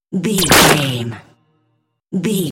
Dramatic hit bloody laser
Sound Effects
heavy
intense
dark
aggressive